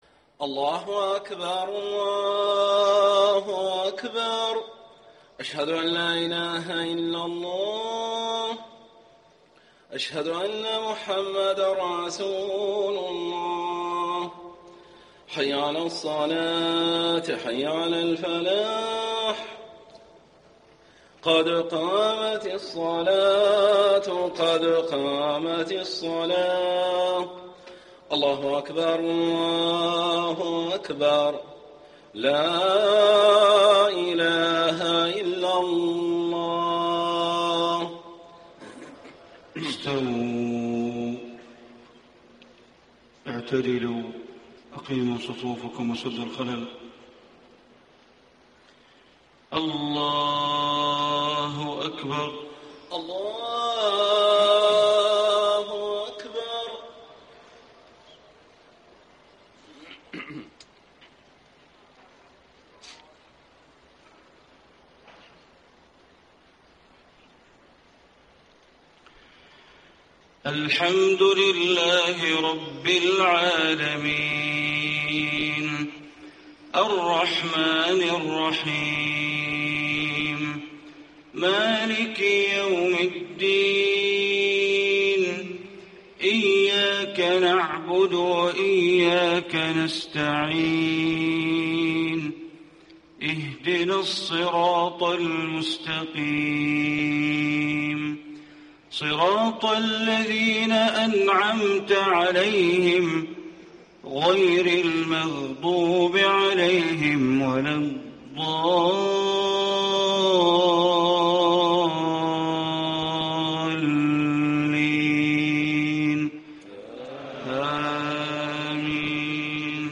صلاة الفجر 7-2-1435 من سورة يوسف > 1435 🕋 > الفروض - تلاوات الحرمين